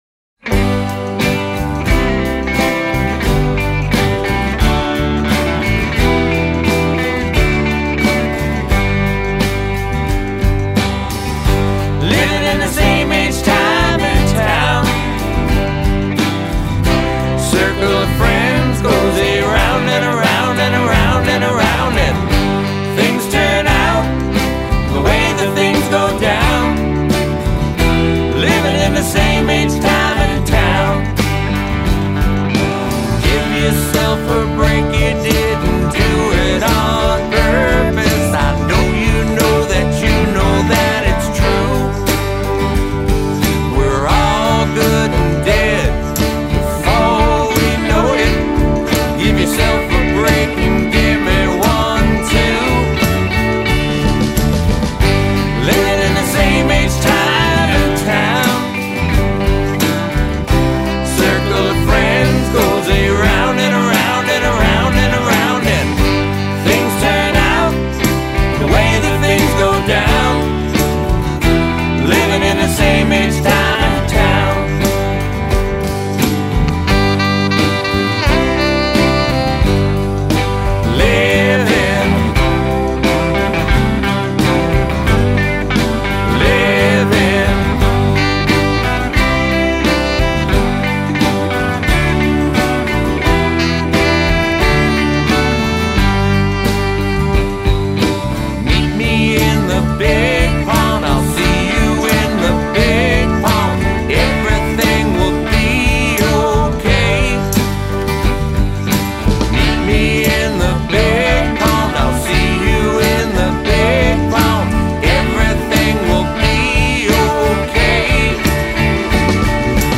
keys
drums, backup vocals
percussion
bass
sax, flute